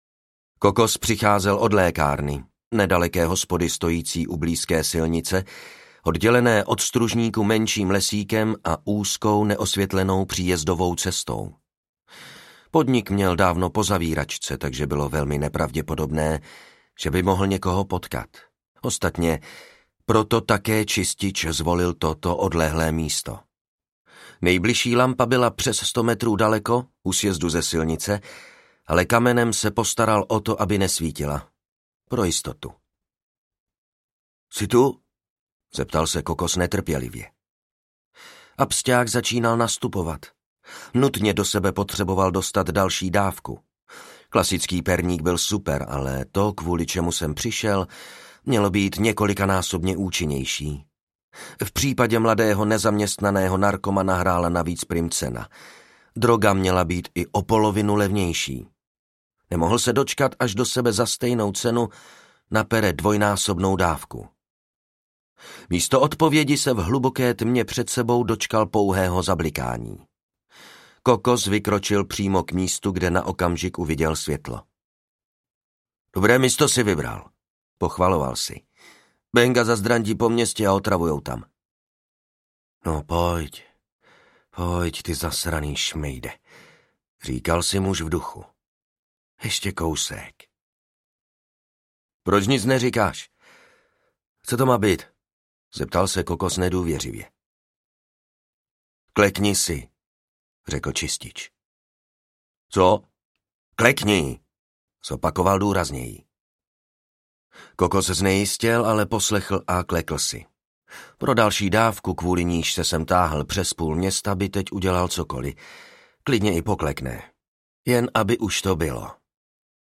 Čistič audiokniha
Ukázka z knihy